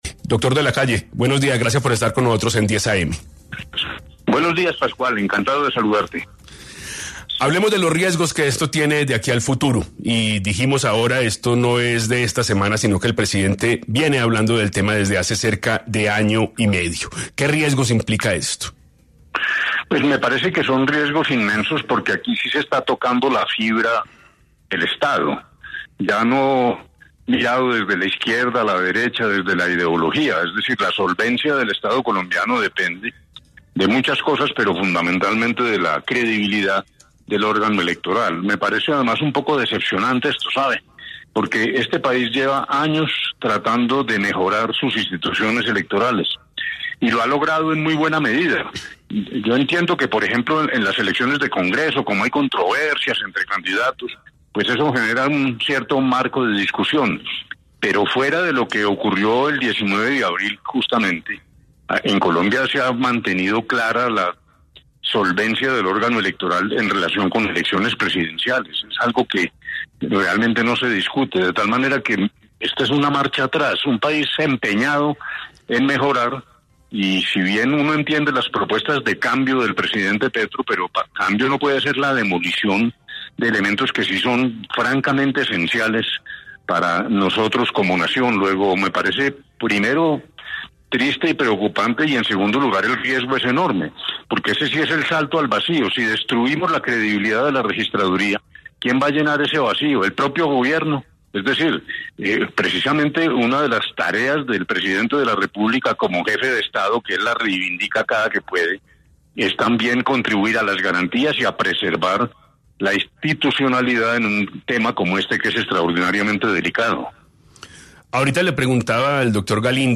El exsenador y exvicepresidente habló en 10AM sobre la credibilidad que tienen los procesos electorales en Colombia y lo que generan las declaraciones del presidente Gustavo Petro.